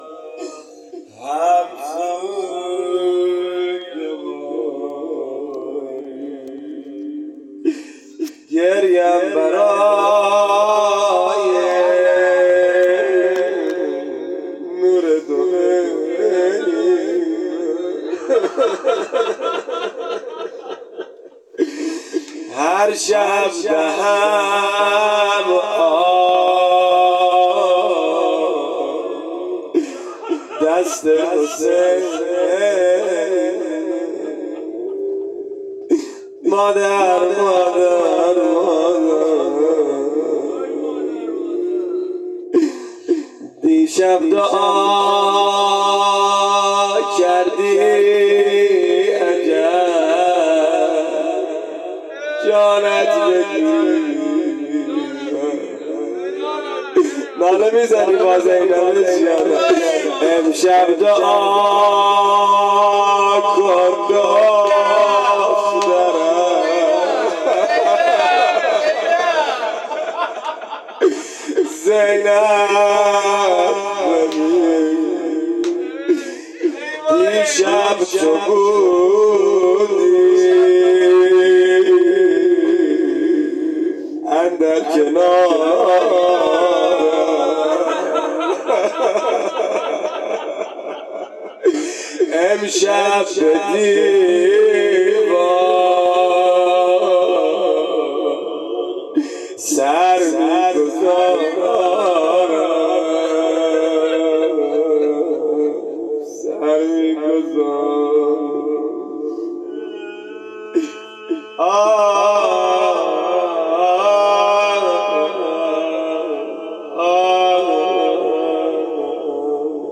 روضه سوزناک